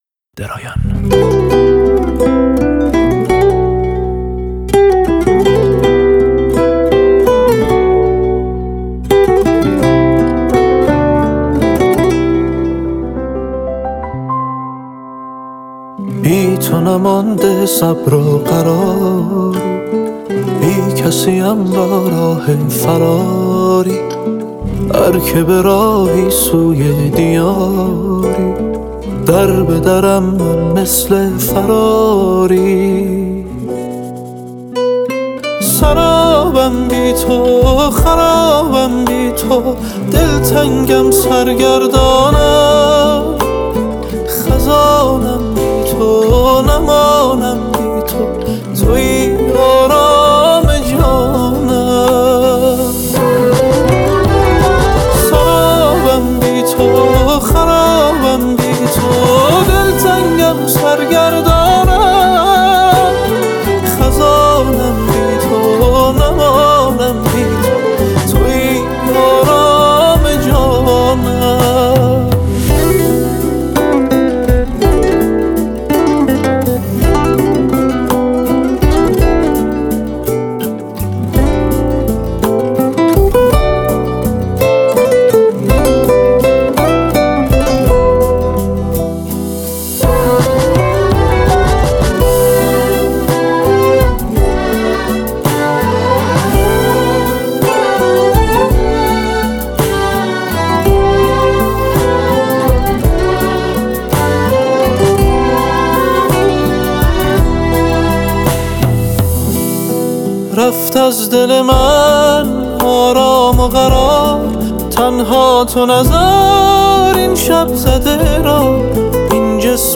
پاپ
آهنگ با صدای زن
آهنگ غمگین